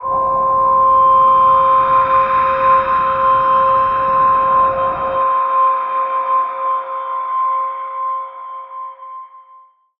G_Crystal-C7-mf.wav